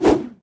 handswing4.ogg